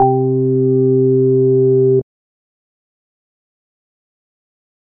Organ (2).wav